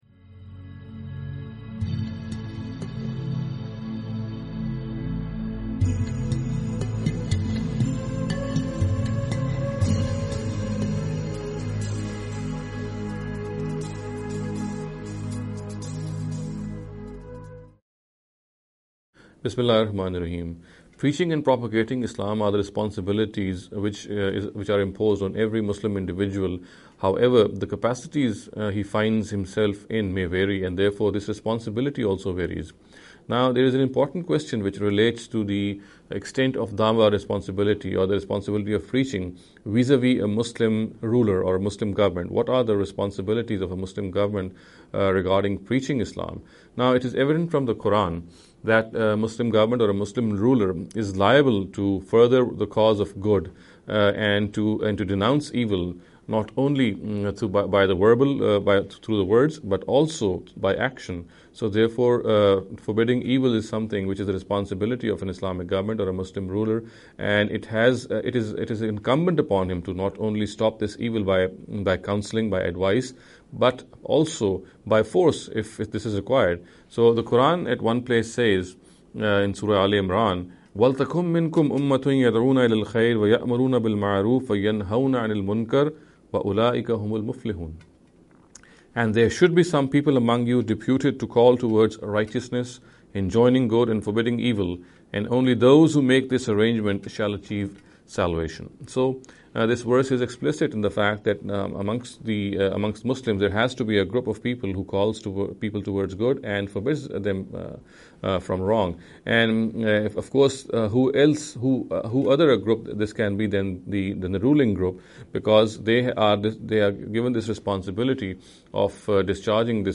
This lecture series will deal with some misconception regarding the Preaching Islam.